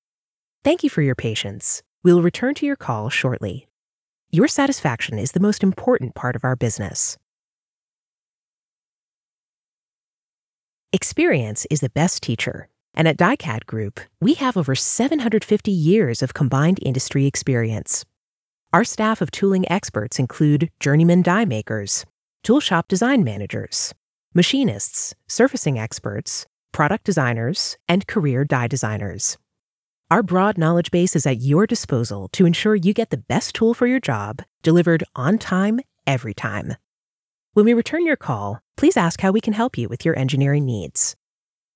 Sample Phone Greeting Scripts